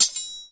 sword_clash.9.ogg